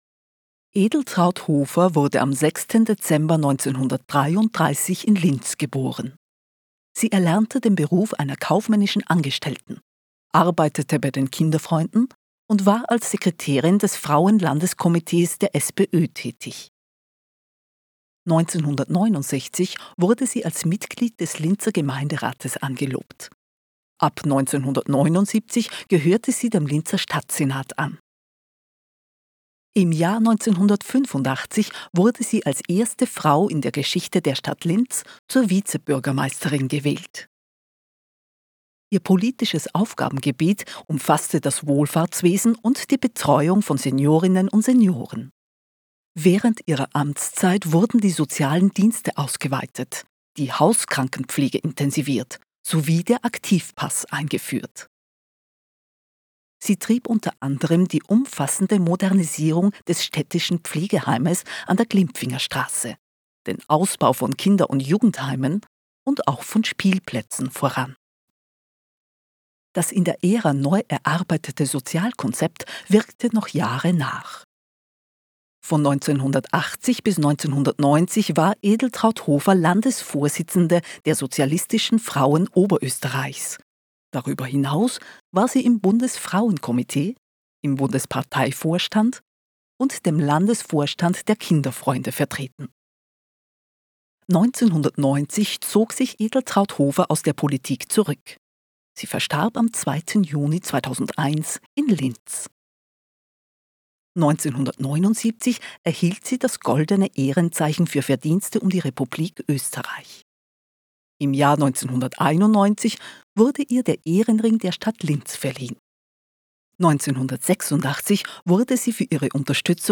Audioguide Edeltraud Hofer Deutsch